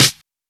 Snares
Csp_Snr3.wav